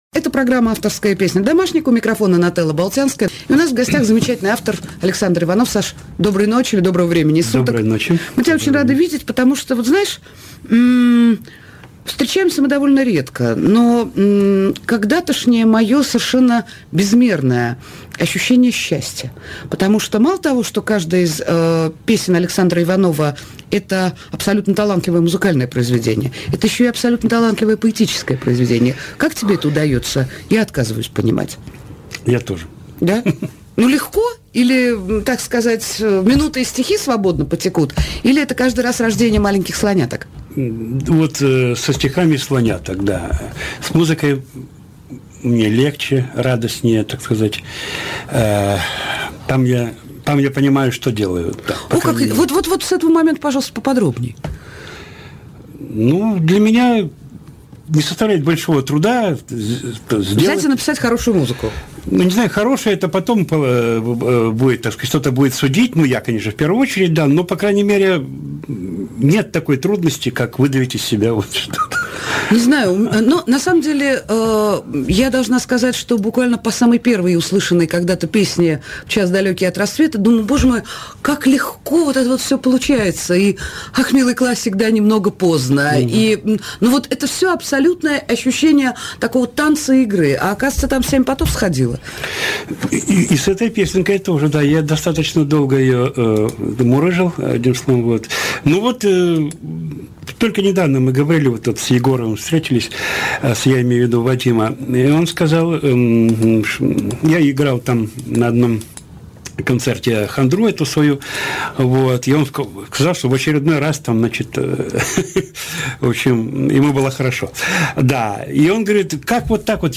на "домашнике" у Н.Болтянской.